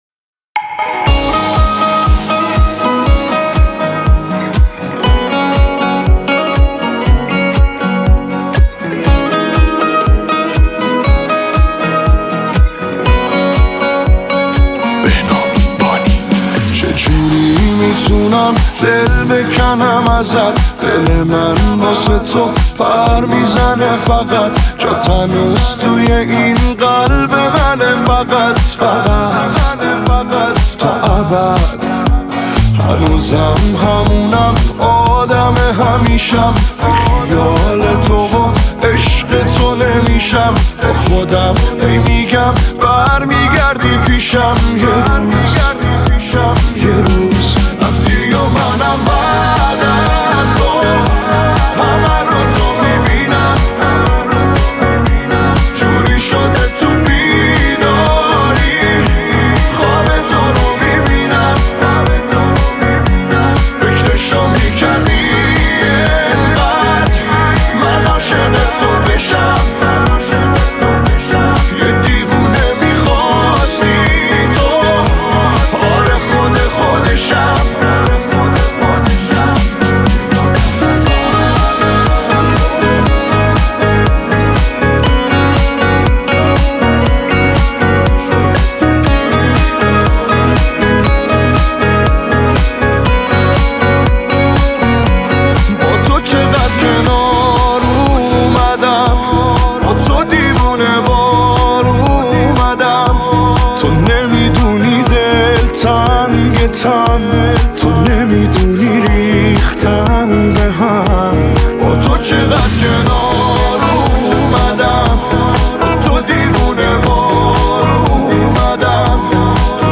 Persian Pop Song